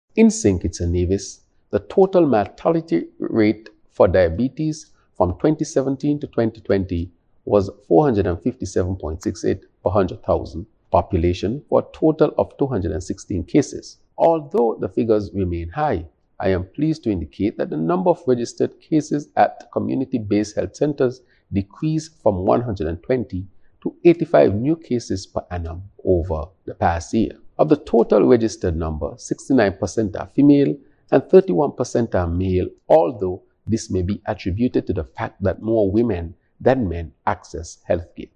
SKN’s Prime Minister and Federal Minister of Health, Hon. Dr. Terrance Drew, in an address to mark the occasion, spoke of the mortality rate.